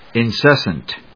音節in・ces・sant 発音記号・読み方
/ɪnsésnt(米国英語), ˌɪˈnsesʌnt(英国英語)/